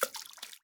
watersplash.wav